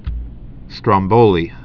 (strŏm-bōlē)